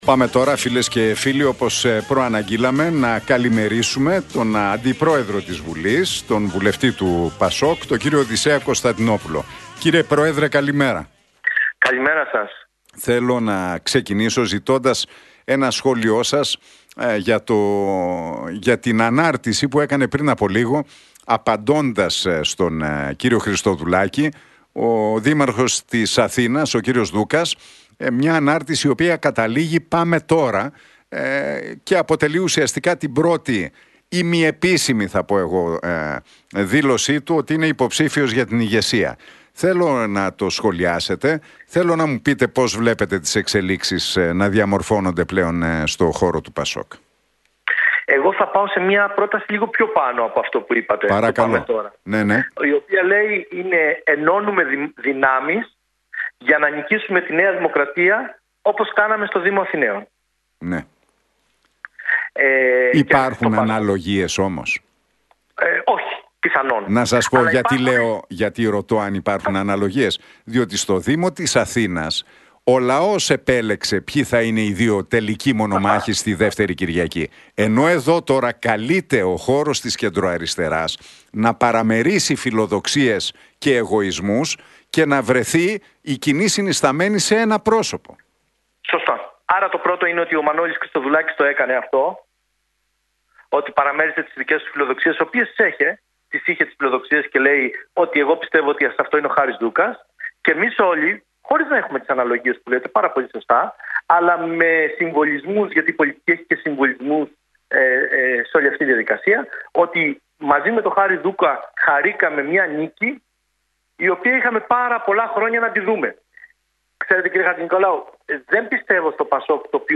«Ενώνουμε δυνάμεις για να νικήσουμε τη ΝΔ όπως κάναμε στον Δήμο της Αθήνας» σχολίασε ο Οδυσσέας Κωνσταντινόπουλος στην εκπομπή του Νίκου Χατζηνικολάου στον Realfm 97,8.